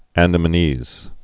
(ăndə-mə-nēz, -nēs)